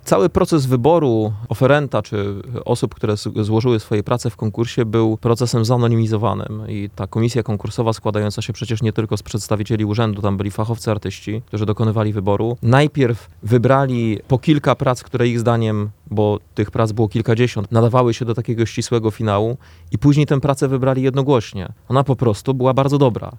– Członkowie Komisji nie znali nazwisk uczestników – mówi prezydent Rafał Zając.